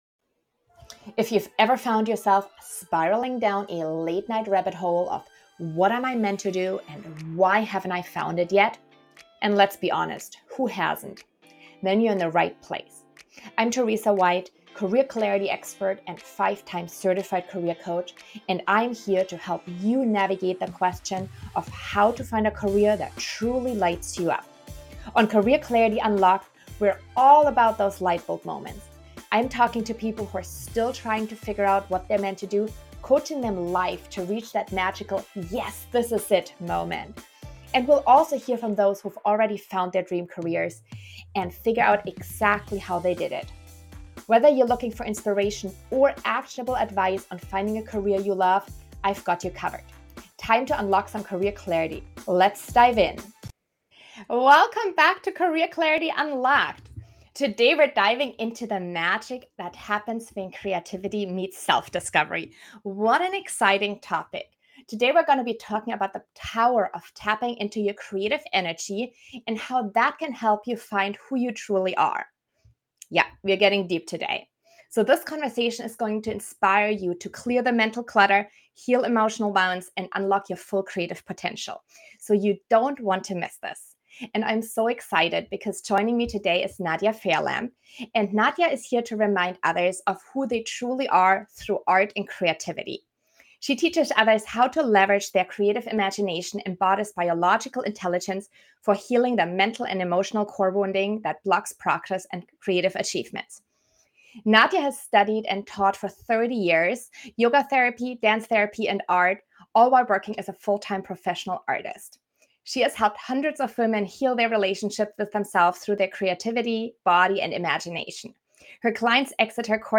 The conversation covers personal experiences, the role of intuitive creativity, and practical strategies for unlocking one’s full potential.